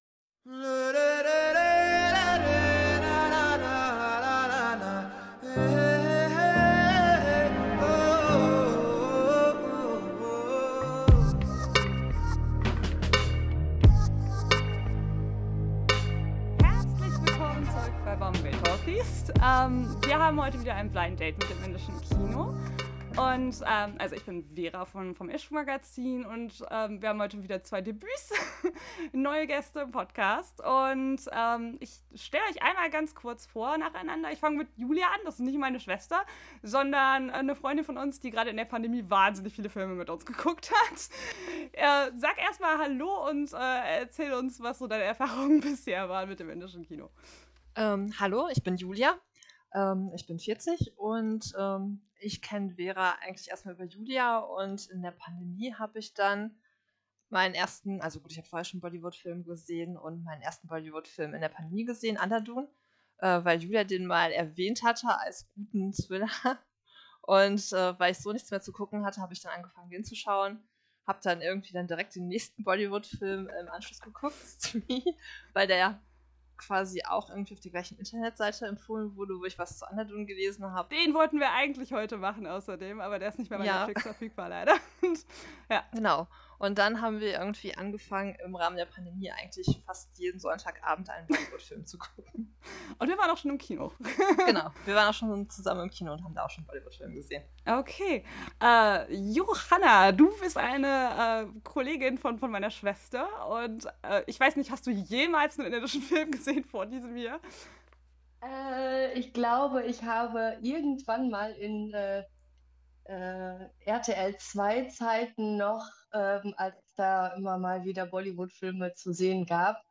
Ausschnitte: 11:05 Clip aus dem Trailer von Ludo Weiterführende Links: Ludo Online Presseonferenz Götter in Ludo Anurag Basu Filmliste von Pinkvilla Die Fortsetzung zu Life… in a Metro ist in Arbeit.